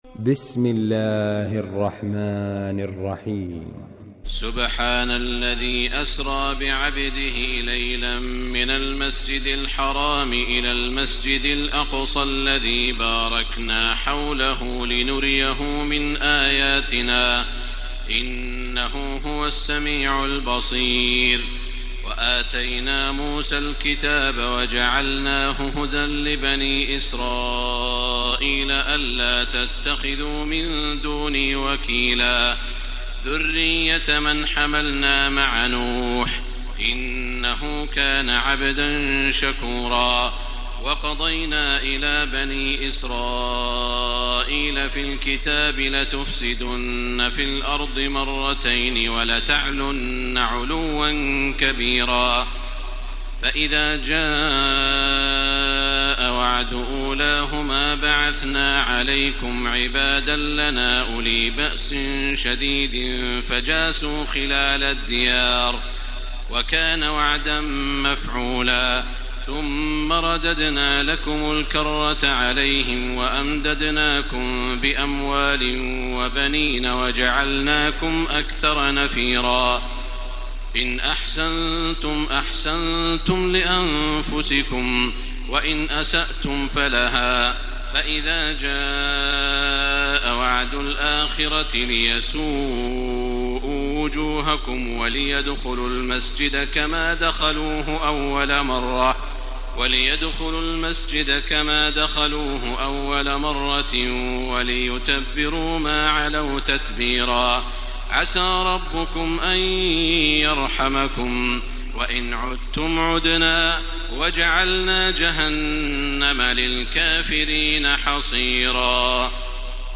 تراويح الليلة الرابعة عشر رمضان 1434هـ من سورة الإسراء (1-96) Taraweeh 14 st night Ramadan 1434H from Surah Al-Israa > تراويح الحرم المكي عام 1434 🕋 > التراويح - تلاوات الحرمين